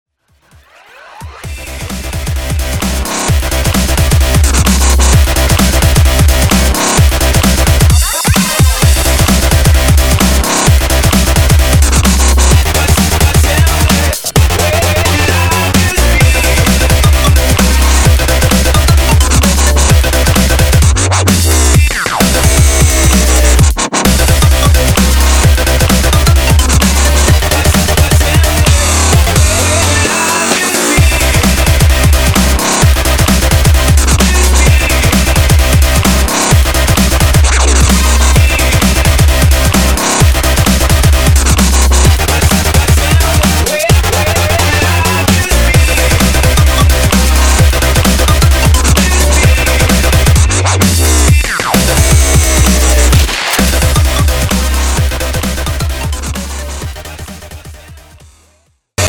• Качество: 320, Stereo
электронные
Клубный убойный рингтончик